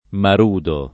[ mar 2 do ]